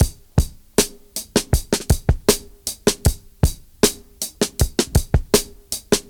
• 79 Bpm Drum Loop Sample C Key.wav
Free drum loop - kick tuned to the C note. Loudest frequency: 2734Hz
79-bpm-drum-loop-c-key-oJ0.wav